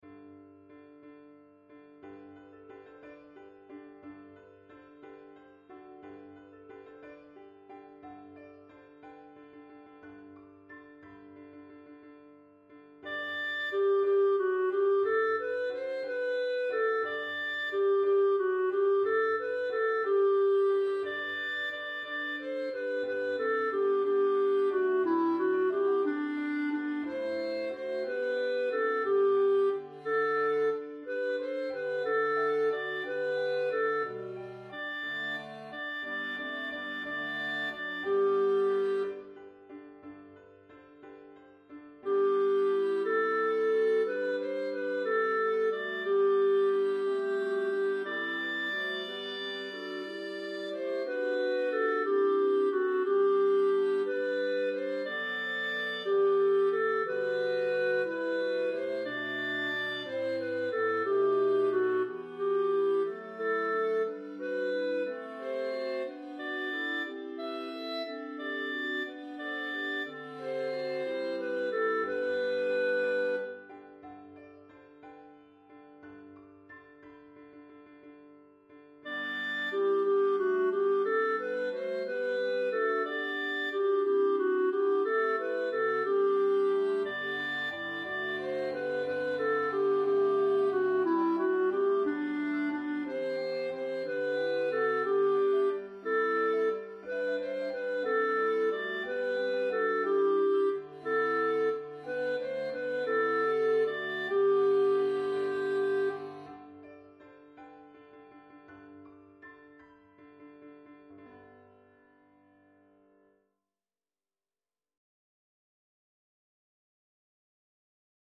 Un Flambeau (arr Rutter)     Your part emphasized:
Soprano     Alto